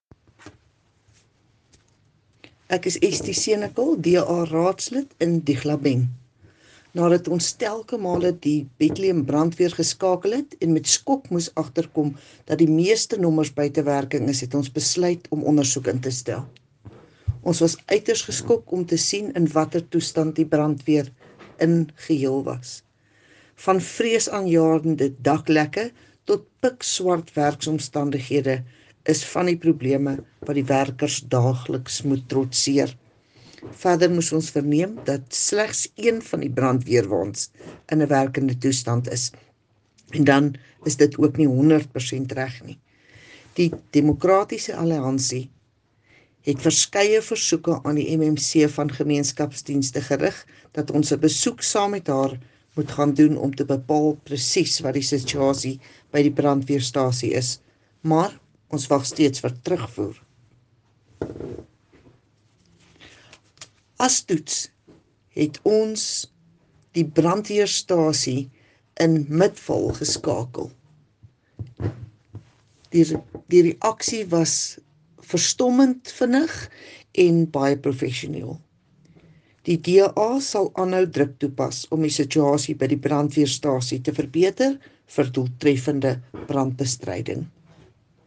Afrikaans soundbites by Cllr Estie Senekal and